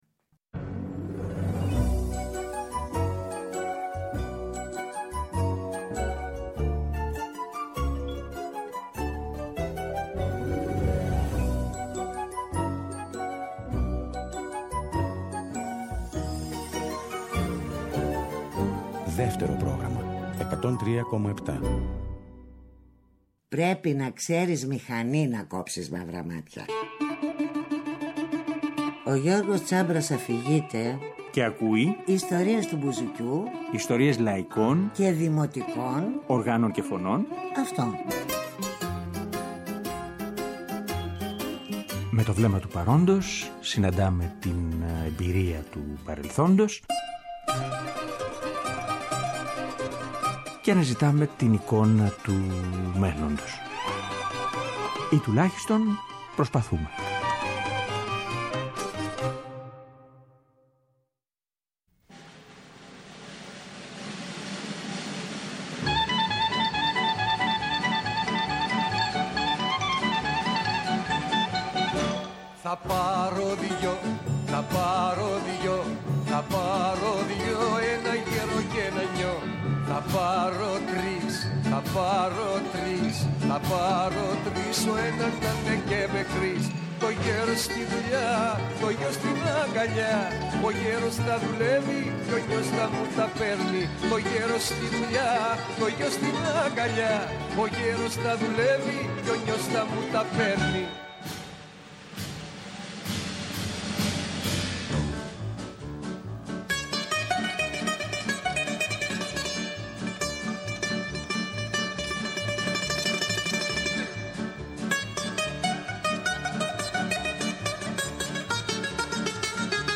Στην εκπομπή, ακούμε γνωστά και λιγότερο γνωστά τραγούδια του, σε εκτελέσεις του γραμμοφώνου, αλλά και νεώτερες.